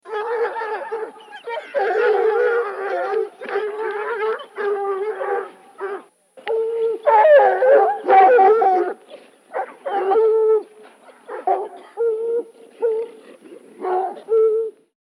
Стая крупных собак